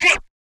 Vox (God).wav